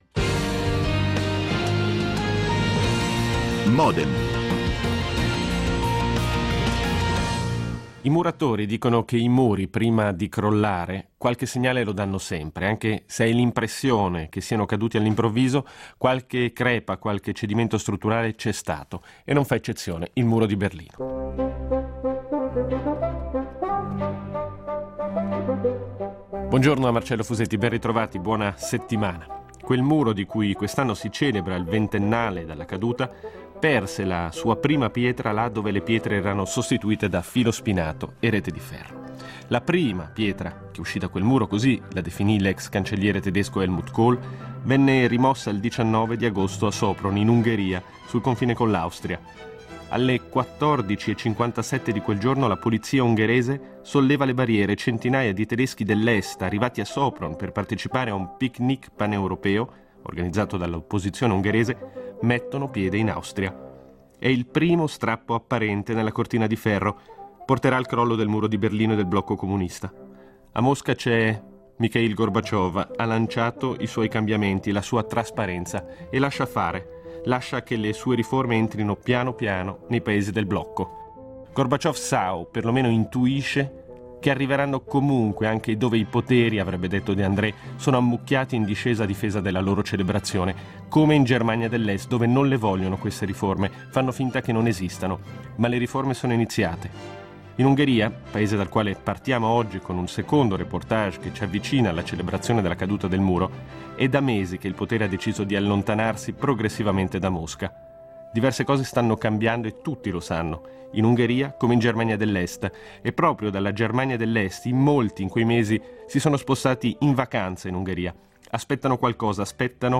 Il muro e l’Ungheria di ieri, oggi e domani in un reportage